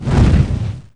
torchon2.wav